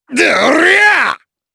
Chase-Vox_Attack4_jp.wav